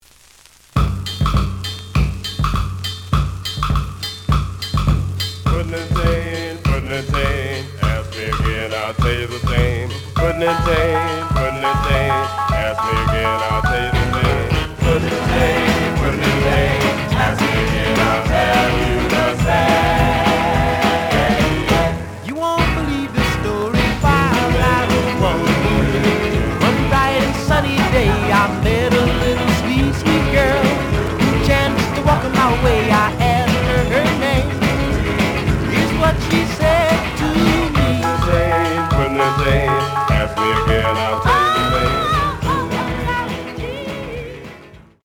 The audio sample is recorded from the actual item.
●Genre: Rhythm And Blues / Rock 'n' Roll
Looks good, but slight noise on both sides.